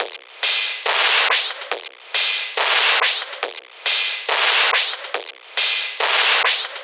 DDW4 LOOP 2.wav